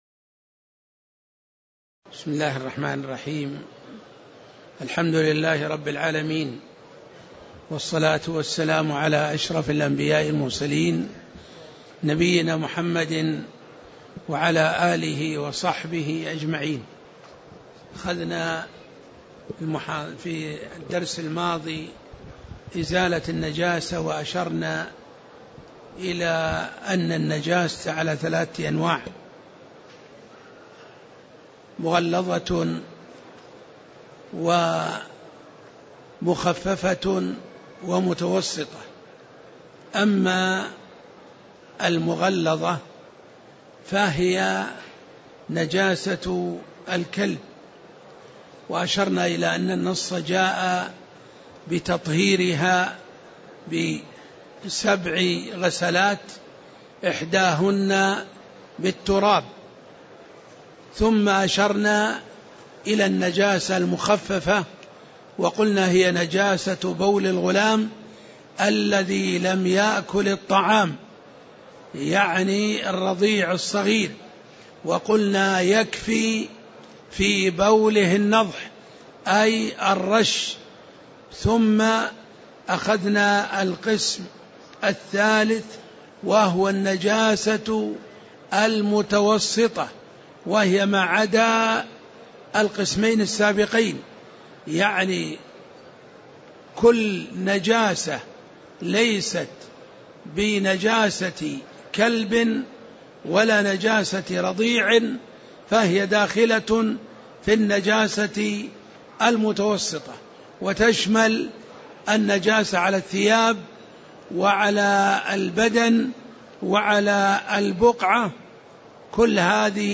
تاريخ النشر ٦ جمادى الآخرة ١٤٣٧ هـ المكان: المسجد النبوي الشيخ